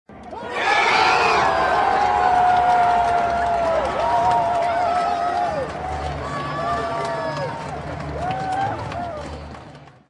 goal-short.mp3